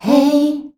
HEY     D.wav